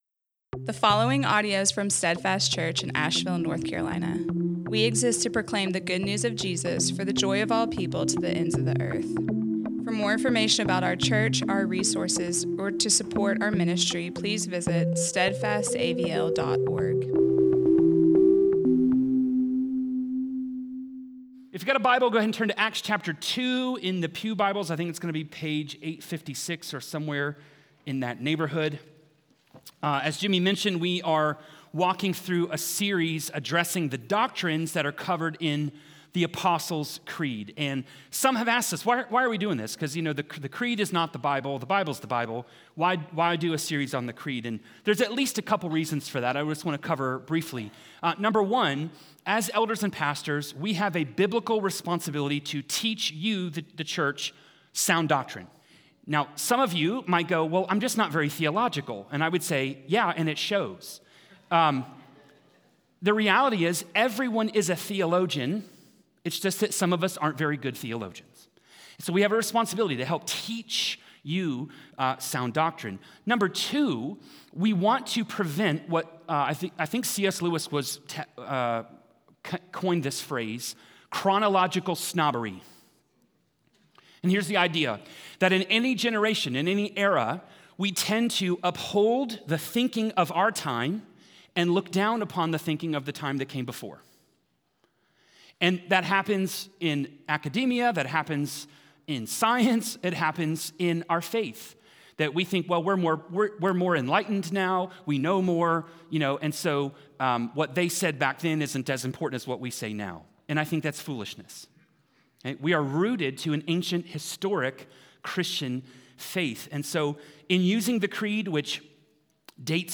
Steadfast Church Sermons